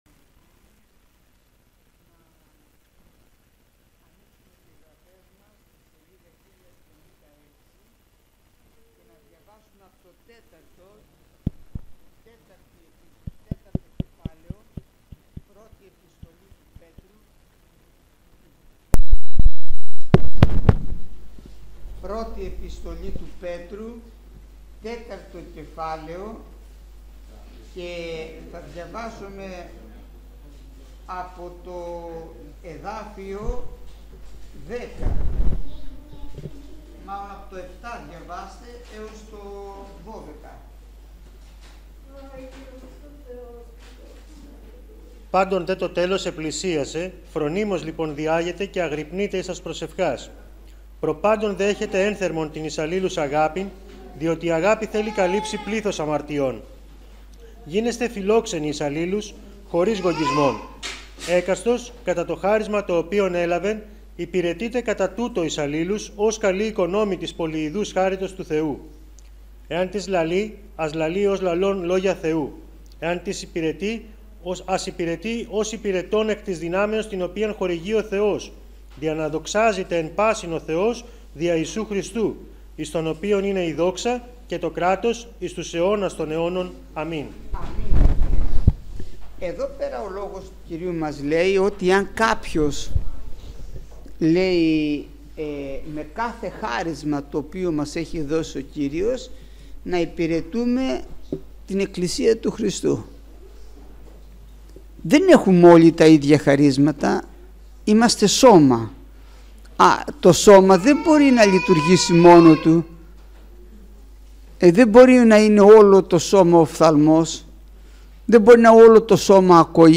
Μήνυμα Θείας Κοινωνίας